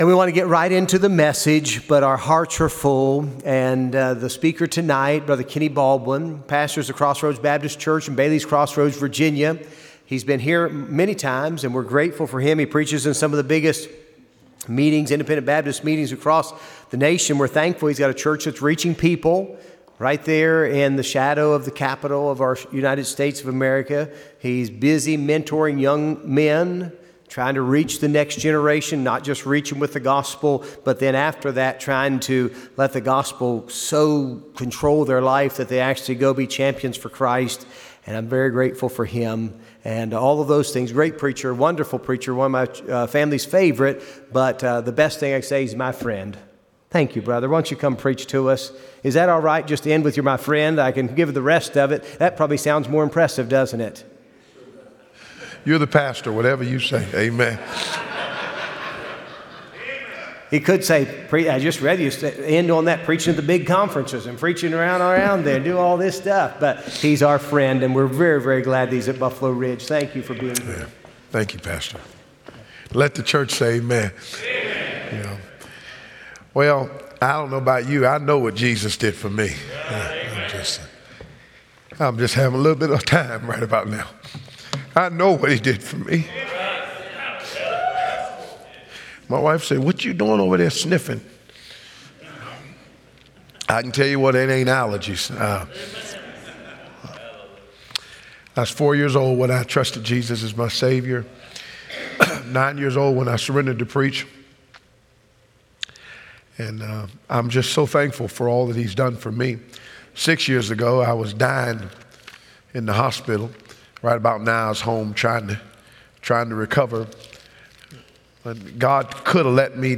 Revival Service